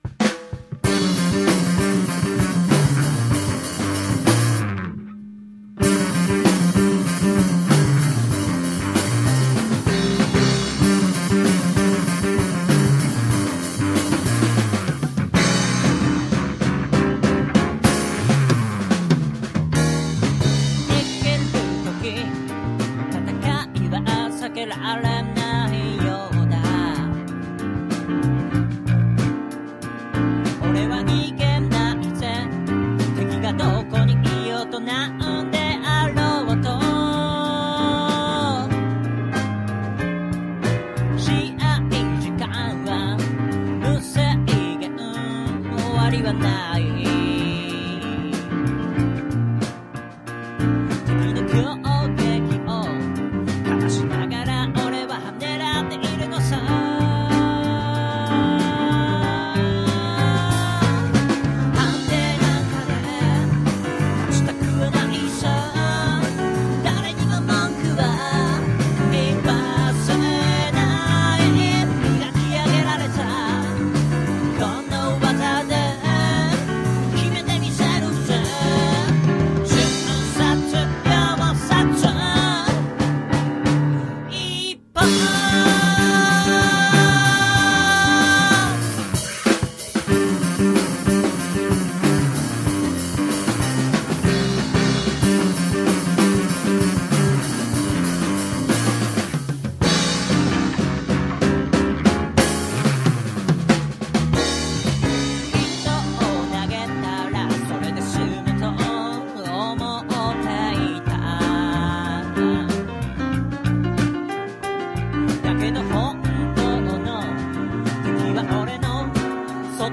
曲がスリリングで、コード付けもいかしてます。演奏も渋い。
ただ、録音があまりよろしくないのと、ボーカルの声質が曲調に合ってないような気もします。喉が締まっているのか、あるいは録音やマイクの使い方でずいぶん変わるのかもしれません。
このベースのファズはいったいどこのメーカーのものなんでしょうか？（笑）